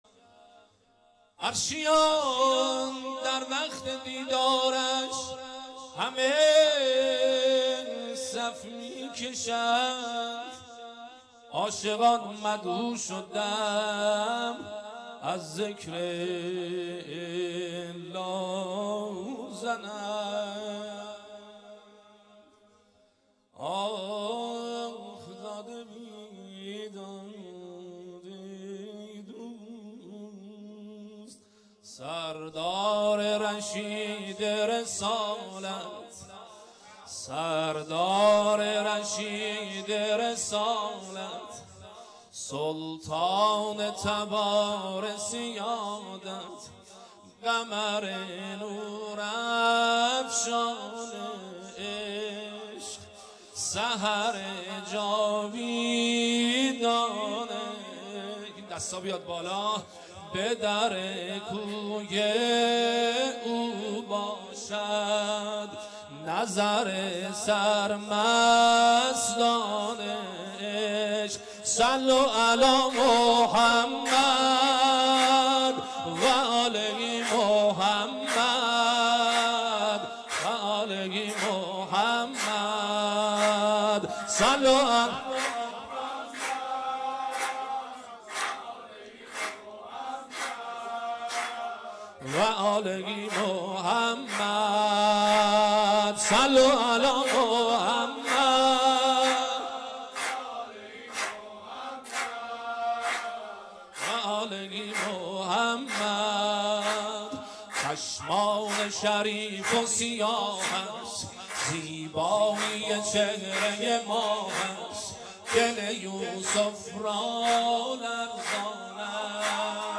ولادت پیامبر اکرم (ص)وامام صادق(ع)96